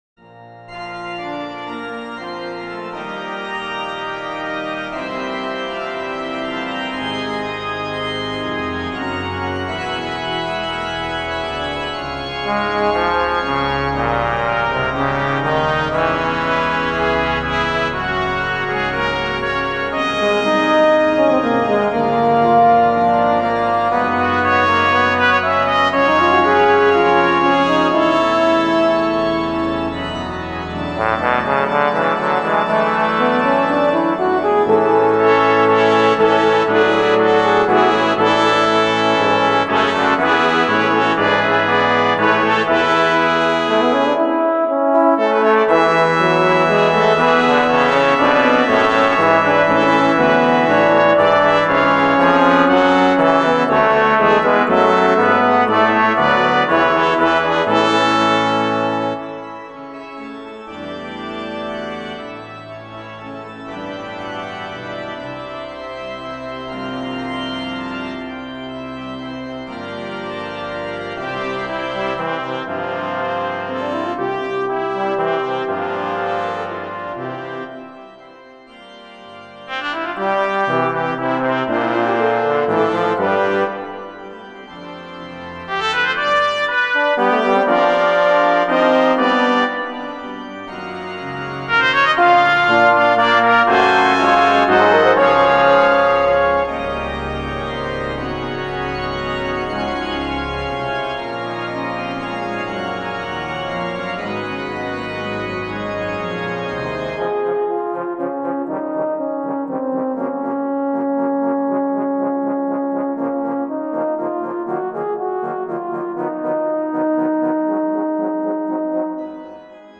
Voicing: 11 Brass